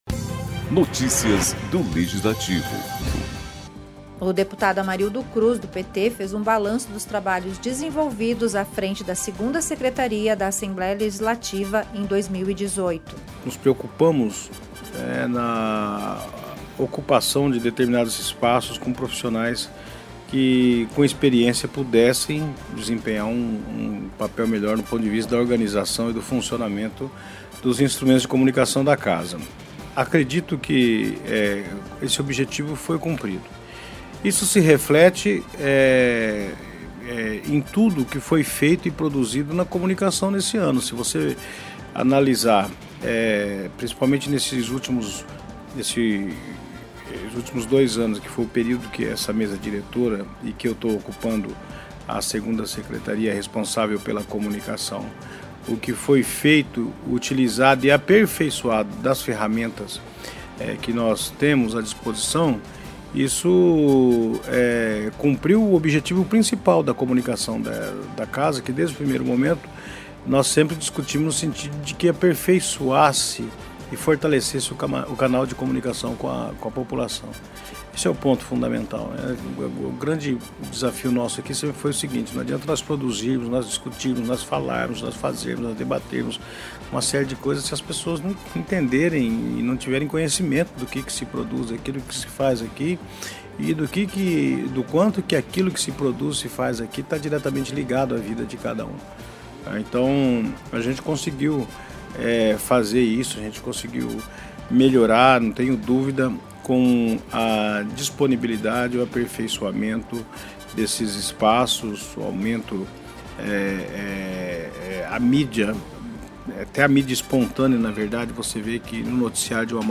Acompanhe o boletim com o 2º secretário da Assembleia Legislativa de Mato Grosso do Sul, deputado Amarildo Cruz (PT), que fez um balanço dos trabalhos desenvolvidos à frente segunda secretaria da Casa de Leis.